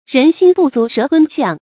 注音：ㄖㄣˊ ㄒㄧㄣ ㄅㄨˋ ㄗㄨˊ ㄕㄜˊ ㄊㄨㄣ ㄒㄧㄤˋ
讀音讀法：
人心不足蛇吞象的讀法